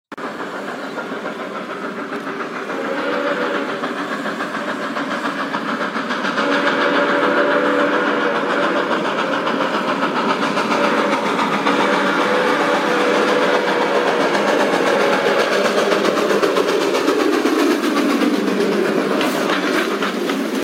Train Sound